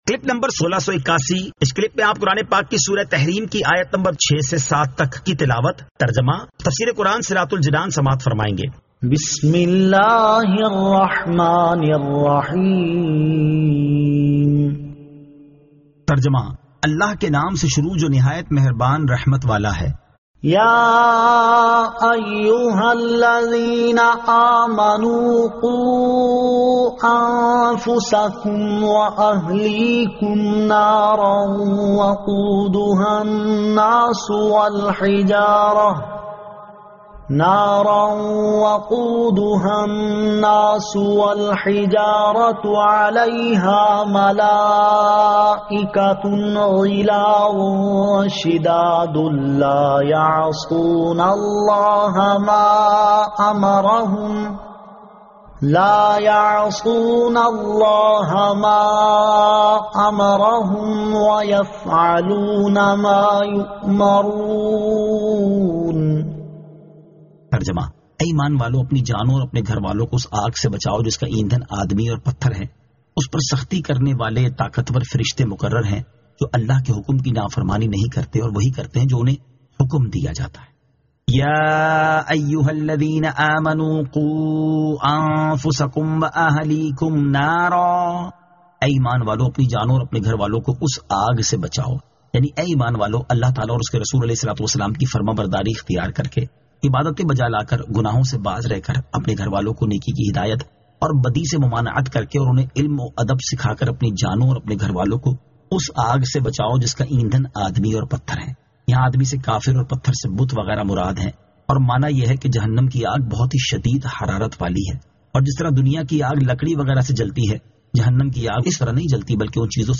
Surah At-Tahrim 06 To 07 Tilawat , Tarjama , Tafseer